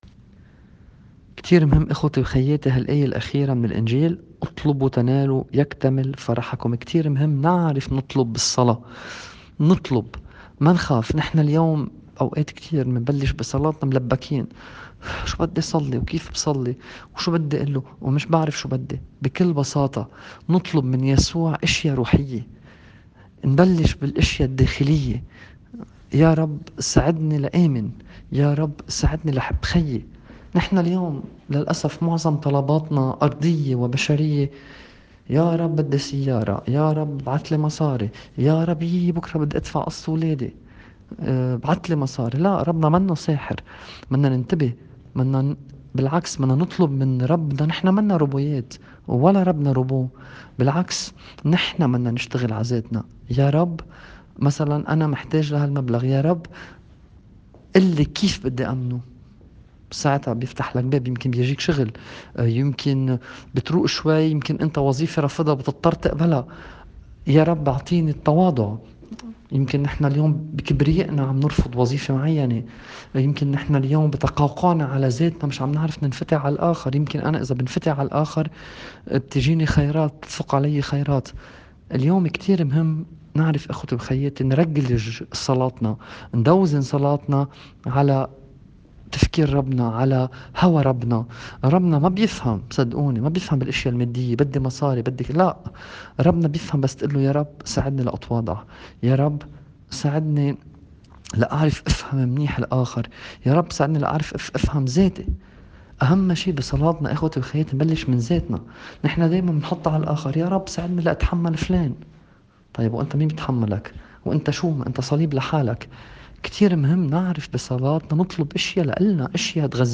تأمّل في إنجيل يوم ١٣ تشرين الأول ٢٠٢٠.mp3